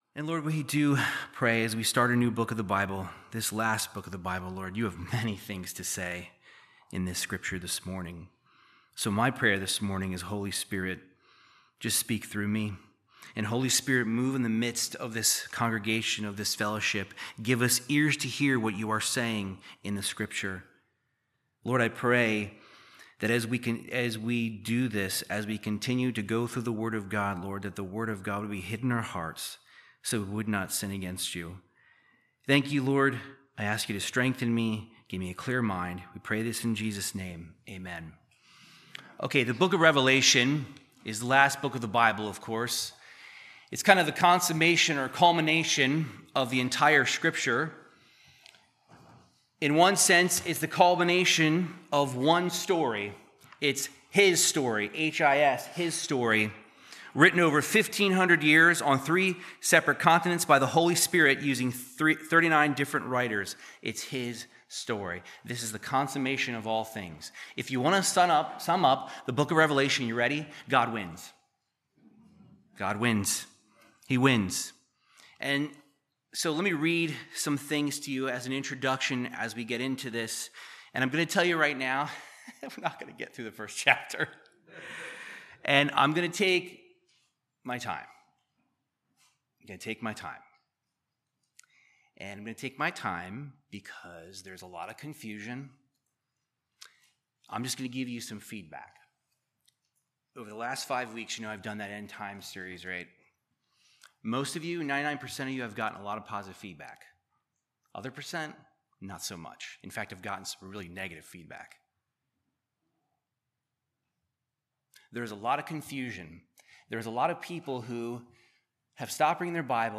Verse by verse Bible teaching through the book of Revelation. This week we discuss Revelation 1:1-8